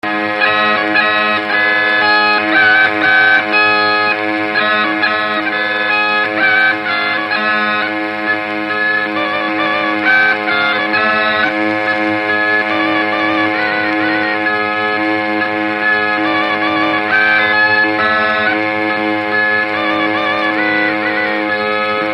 Dallampélda: Hangszeres felvétel
Felföld - Nógrád vm. - Kishartyán
Műfaj: Lassú csárdás
Stílus: 1.1. Ereszkedő kvintváltó pentaton dallamok